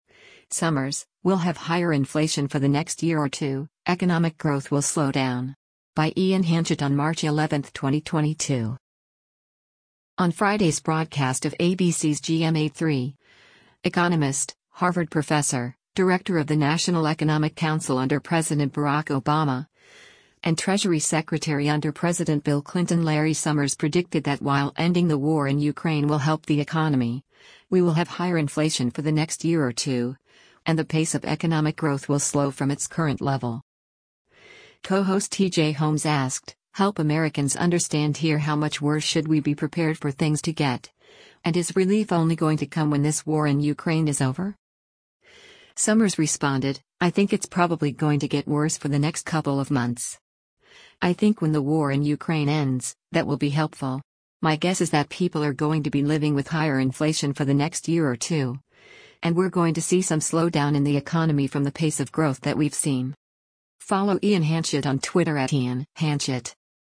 On Friday’s broadcast of ABC’s “GMA3,” economist, Harvard Professor, Director of the National Economic Council under President Barack Obama, and Treasury Secretary under President Bill Clinton Larry Summers predicted that while ending the war in Ukraine will help the economy, we will have “higher inflation for the next year or two,” and the pace of economic growth will slow from its current level.
Co-host T.J. Holmes asked, “Help Americans understand here how much worse should we be prepared for things to get, and is relief only going to come when this war in Ukraine is over?”